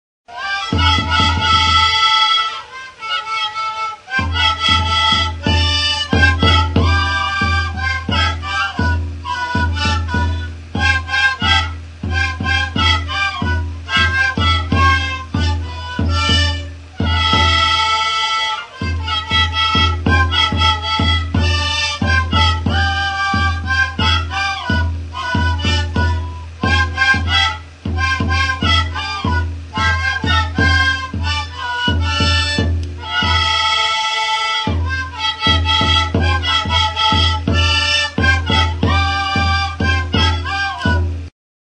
Aerophones -> Flutes -> Fipple flutes (two-handed) + kena
AMERICA -> BOLIVIA
Bi eskuko flauta zuzena da. 6 zulo ditu aurrekaldean.